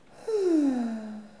ah-soulagement_06.wav